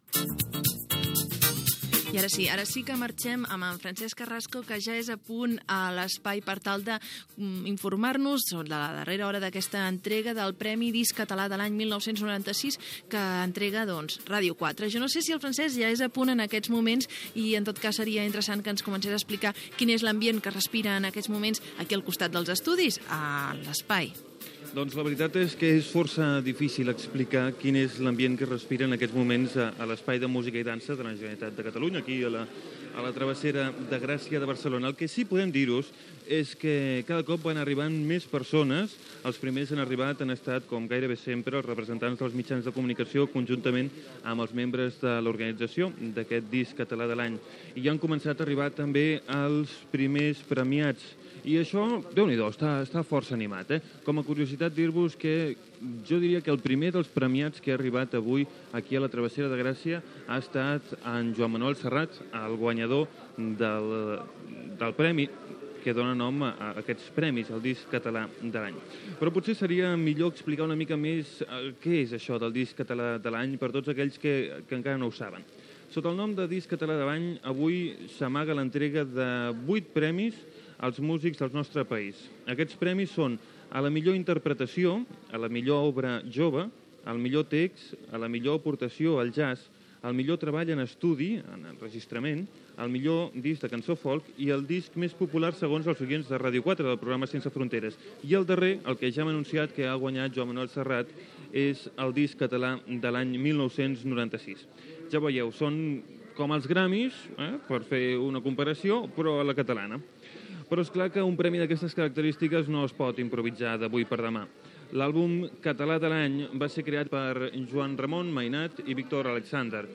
8fb0604cbdd0e6d7bc20cfd4afc890fdfe20461d.mp3 Títol Ràdio 4 Emissora Ràdio 4 Cadena RNE Titularitat Pública estatal Nom programa L'autobús Disc català de l'any Descripció Connexió amb l'Espai de Barcelona on es lliuren els premis Disc català de l'any 1996. Informació dels premis, explicació de quan es van crear, repàs a alguns dels guanyadors.
Entreteniment Musical